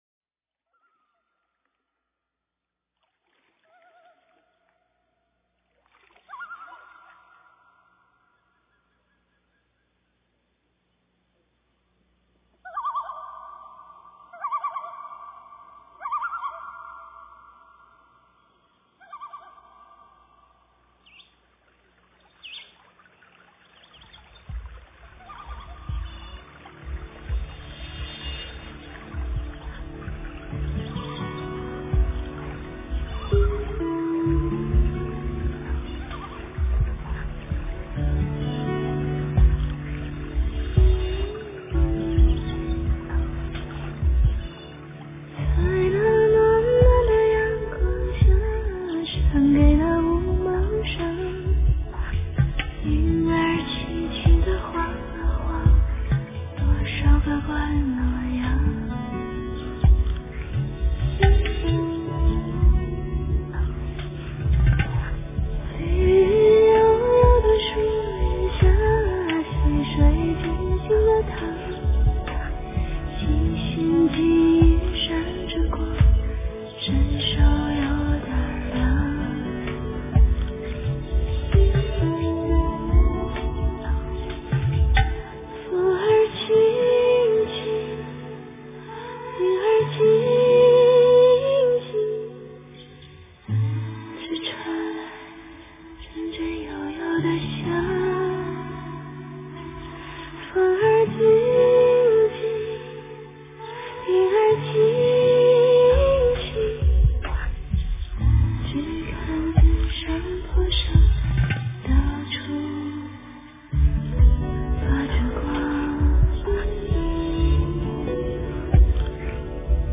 佛音 诵经 佛教音乐 返回列表 上一篇： 大悲咒-梵音 下一篇： 般若波罗蜜多心经 相关文章 观音灵感歌(童音版